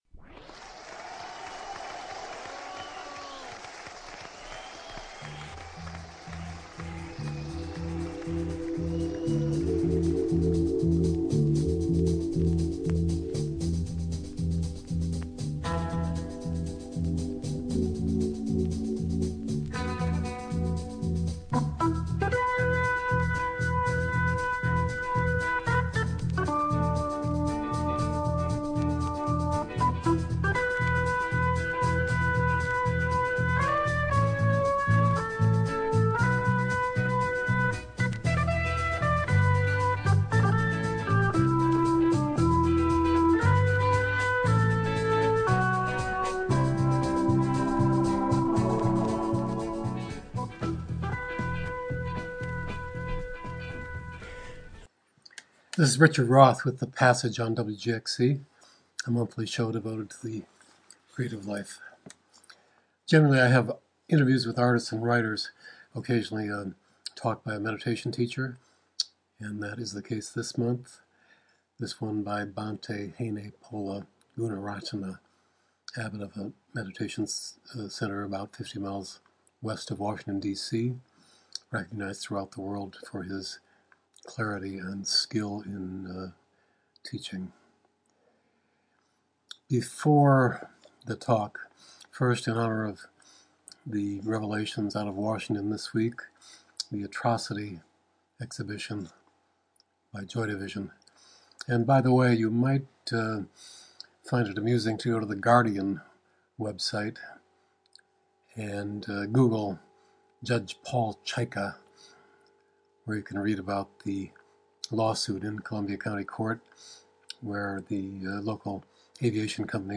An informal talk by Bhante H. Gunaratana on the way to achieve world peace.